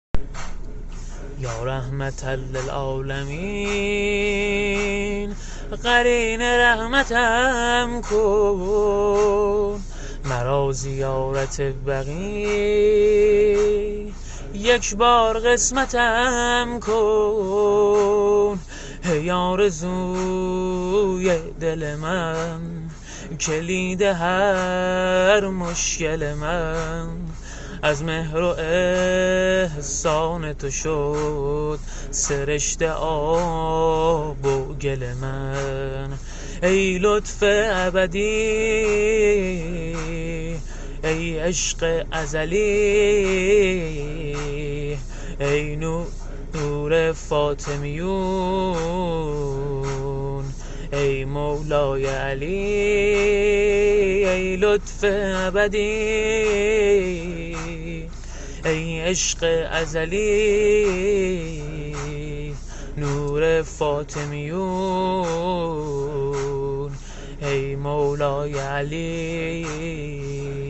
زمینه شهادت پیامبر رحمت صل الله علیه و آله -(یا رحمت الالعالمین، قرین رحمتم کن )